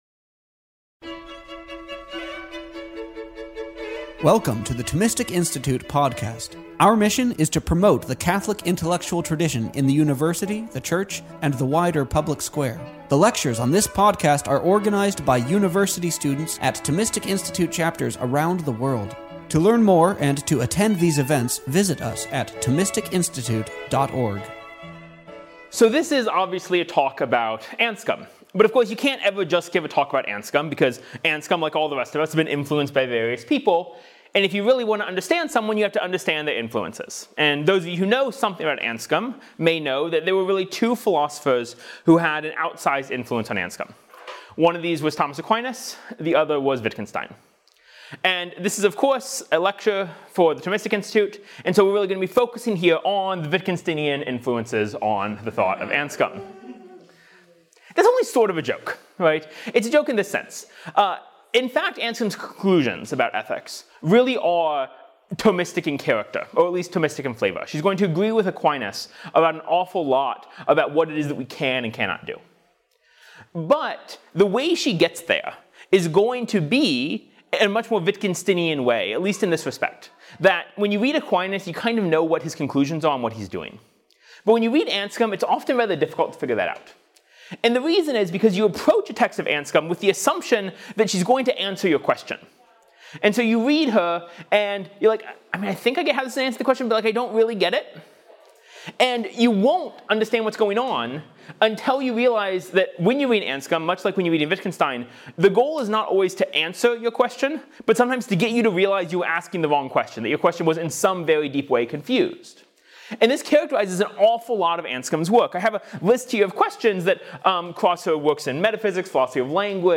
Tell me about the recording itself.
A lecture on November 5, 2016 at New York University.